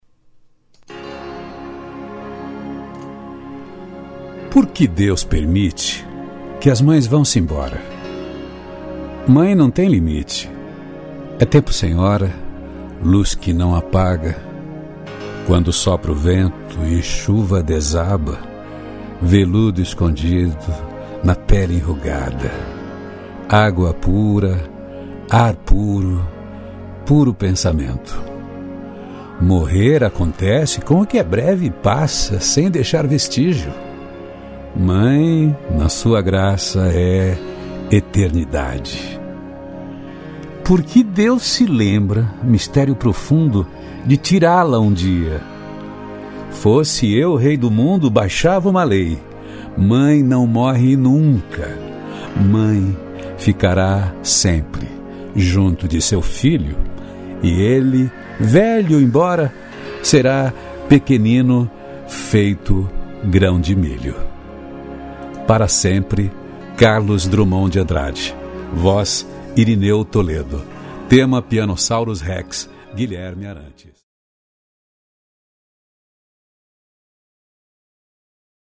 trilha de Guilherme Arantes
Tema Pianossauros Rex de Guilherme Arantes